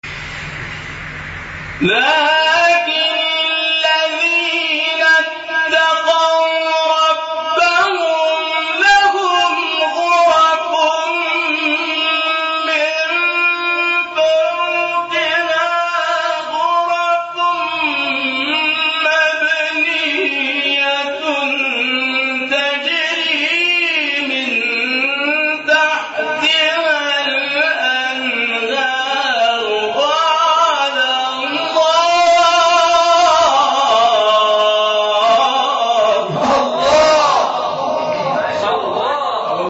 شبکه اجتماعی: فرازهای صوتی از تلاوت قاریان ممتاز و بین المللی کشور که به تازگی در شبکه‌های اجتماعی منتشر شده است، می‌شنوید.